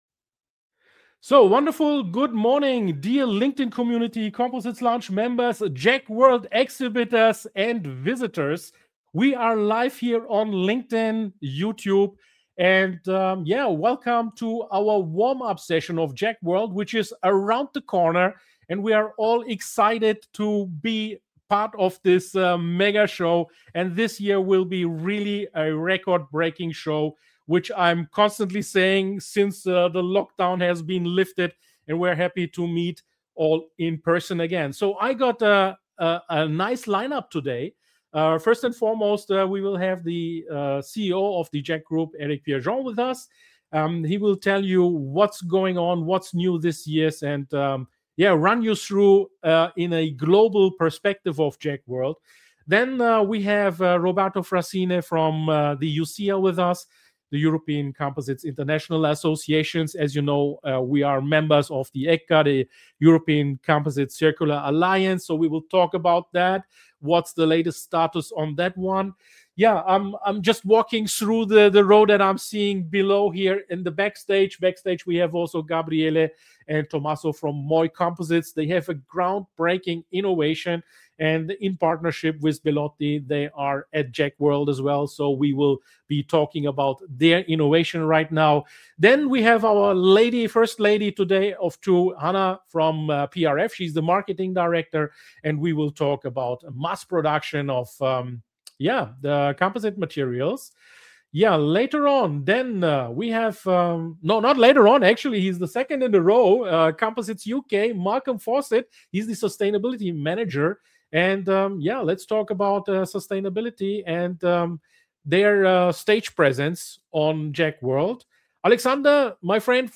Imagine this: I’m packing my virtual bags, polishing the digital microphones, and rolling out the virtual carpet — because next week we go LIVE to warm you up for the biggest composites event on the planet....